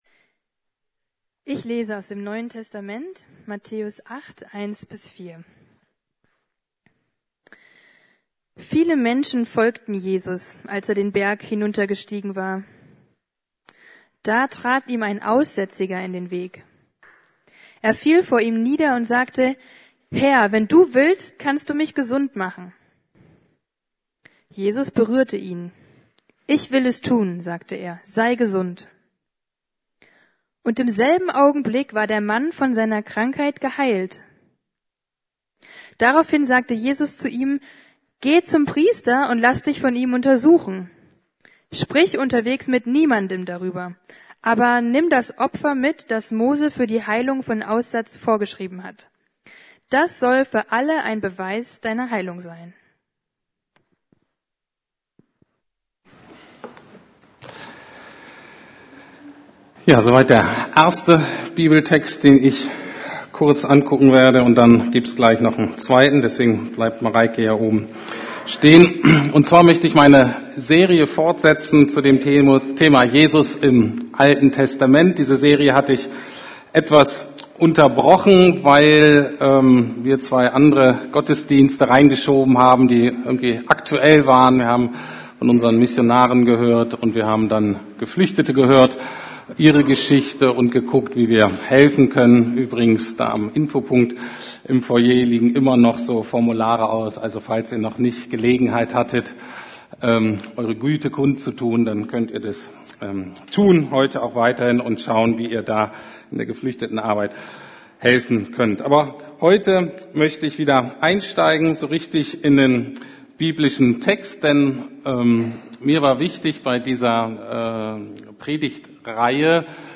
Jesus im Alten Testament 6 ~ Predigten der LUKAS GEMEINDE Podcast